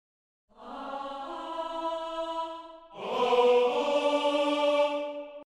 Bei den schnelleren Passagen kommen die Damen und Herren aber etwas ins Straucheln.
Hier ist alles noch Midi. Ich habe bei den Auftaktnoten nun mal etwas die Lautstärke bearbeitet ... komischerweise klingt es bei den Jungs realistischer als bei den Mädels.
Der Stereo-Double-Effekt haut bei mir Glitches rein, daher hier ausgeschaltet.